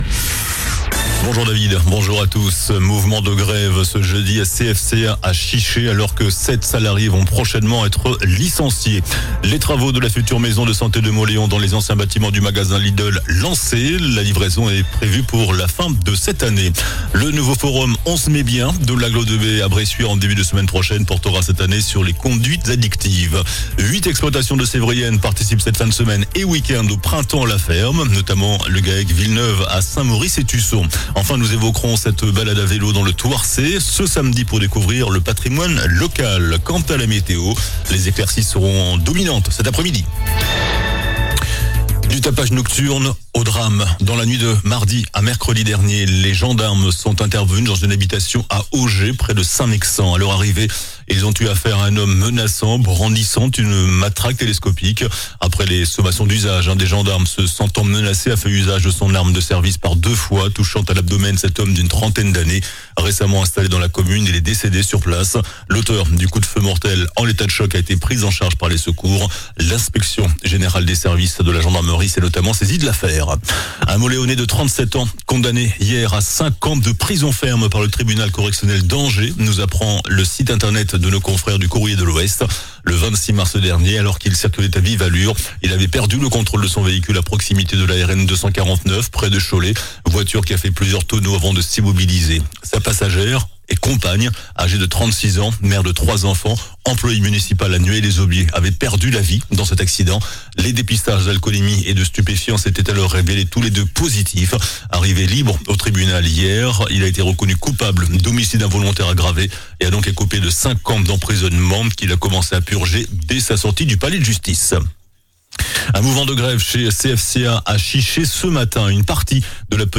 JOURNAL DU JEUDI 22 MAI ( MIDI )